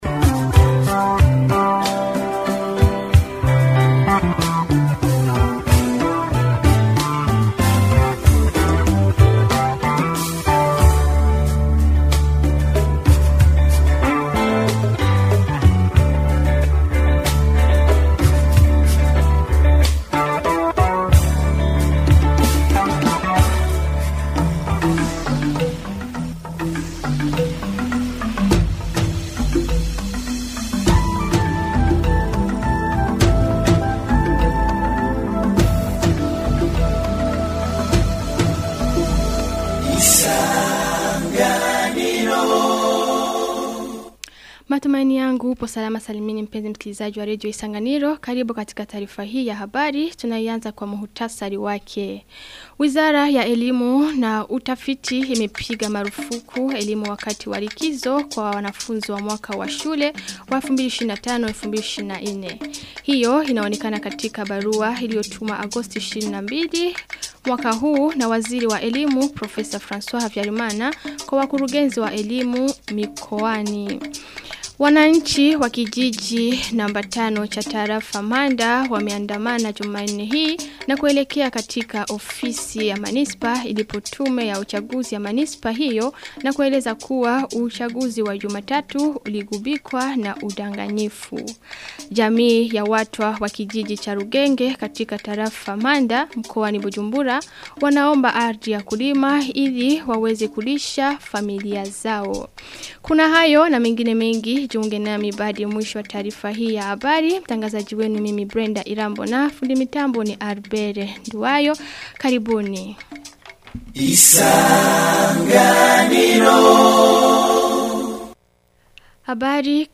Taarifa ya habari ya tarehe 26 Agosti 2025